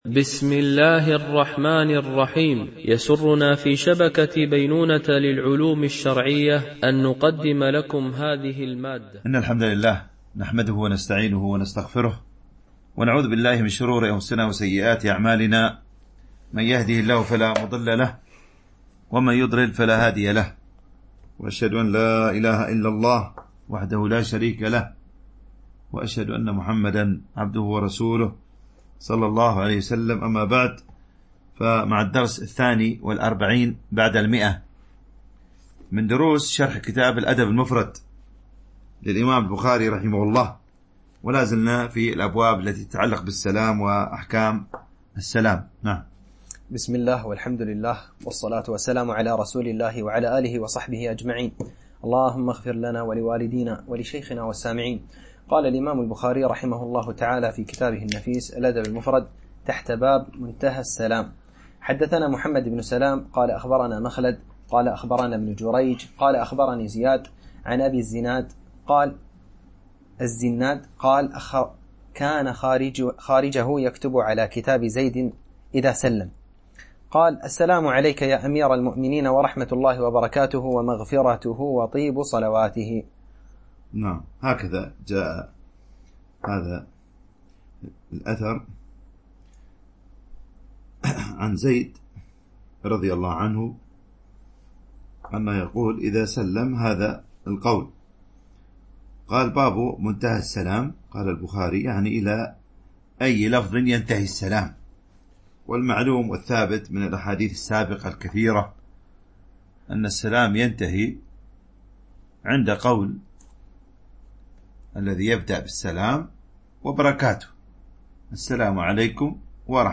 شرح الأدب المفرد للبخاري ـ الدرس 142 (الحديث 1001 - 1012 )